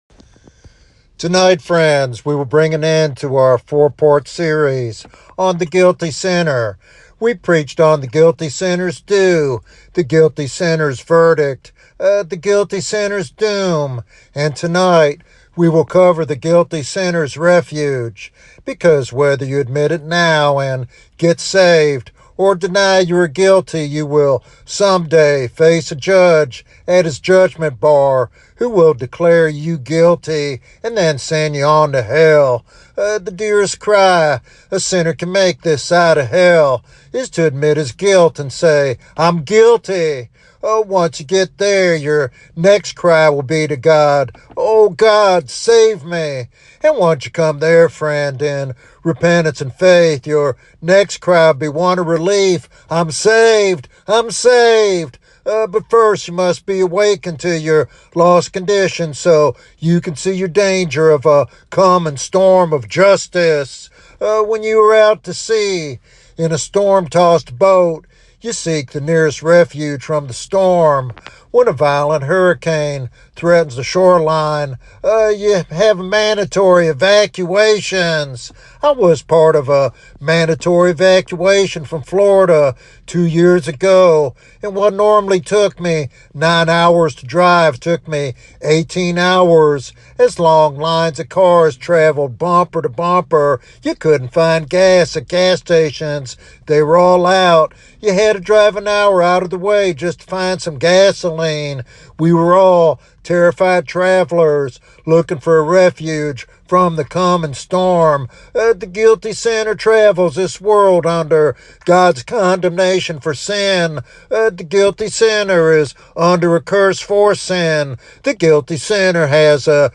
This sermon serves as a powerful evangelistic invitation to flee to Christ before the coming judgment.